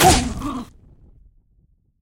beast_hit.ogg